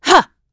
Isolet-Vox_Attack2.wav